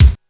Kick 01.wav